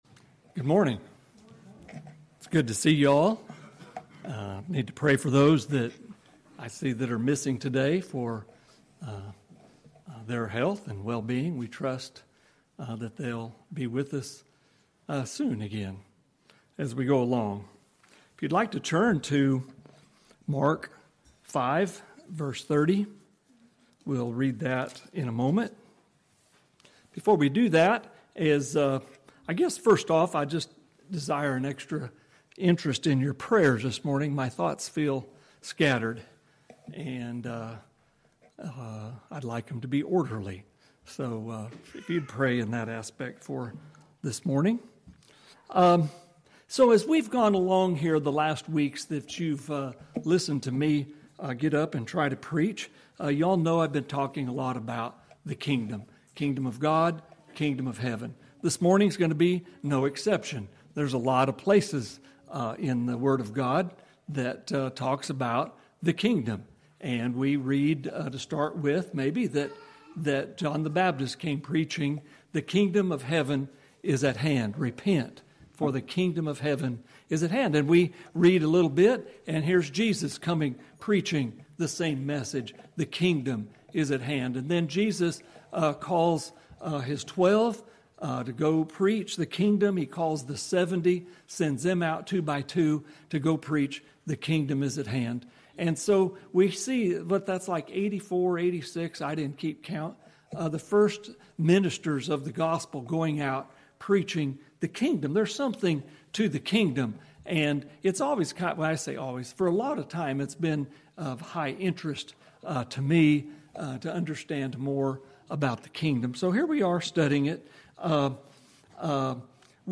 preaching on the parable of the grain of mustard seed (Mark 4).